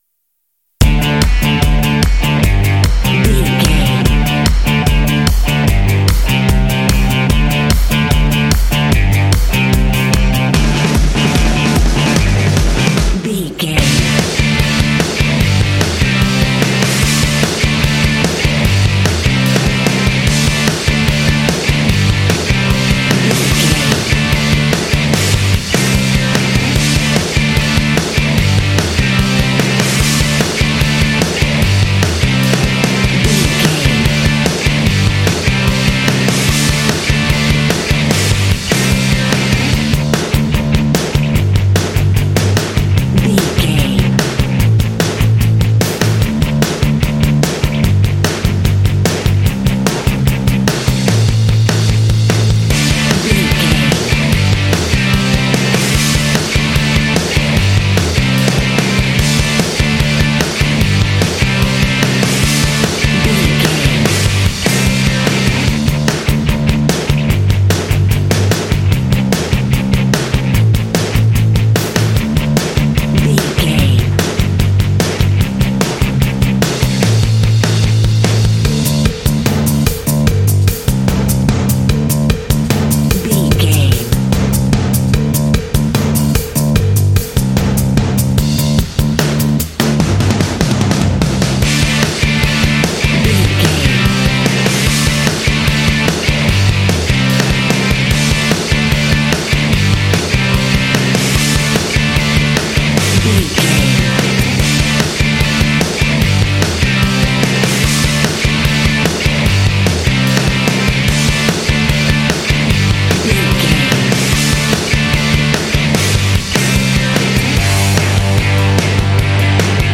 This cute indie track is ideal for action and sports games.
Uplifting
Ionian/Major
D
Fast
energetic
cheerful/happy
electric guitar
bass guitar
drums
classic rock
alternative rock